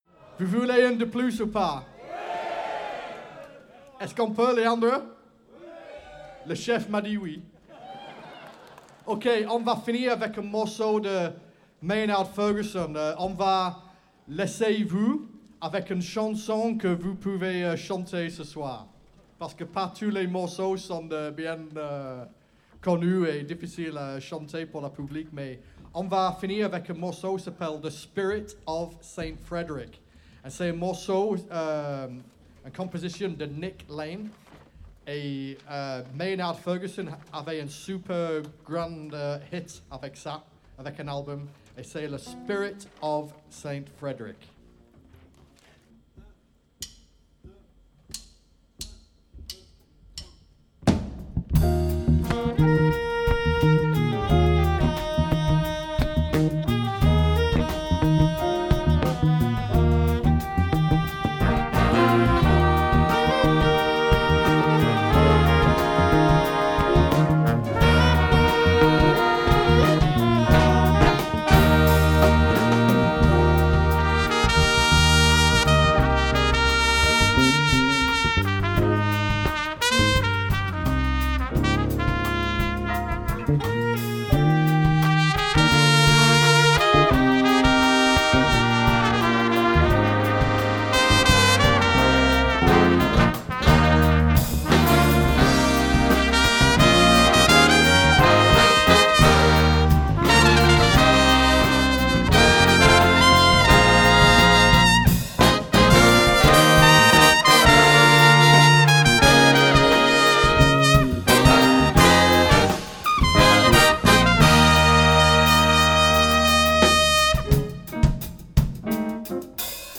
Festival 2025 de Big Bands de Pertuis, 5 soirées, 10 concerts dont 4 gratuits, le jazz sous toutes ses formes: Blues, Funk, New Orleans, Salsa, Swing etc....................
Le Big Band de Pertuis